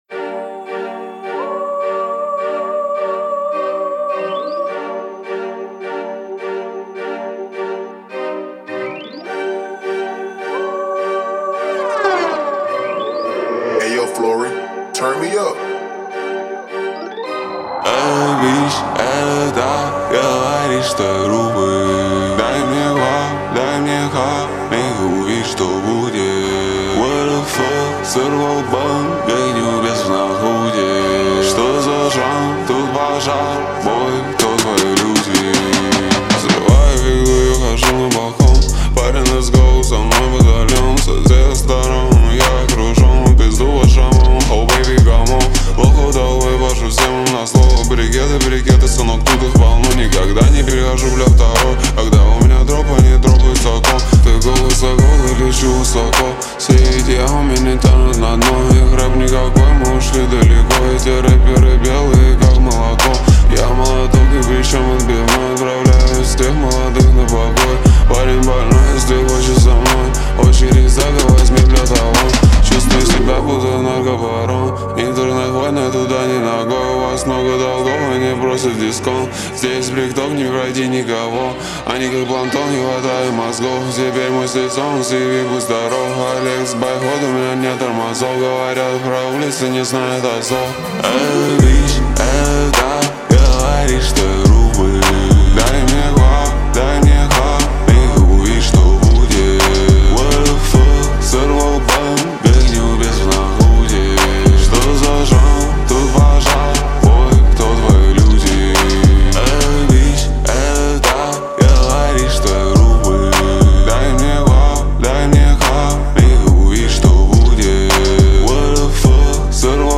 это атмосферный трек в жанре хип-хоп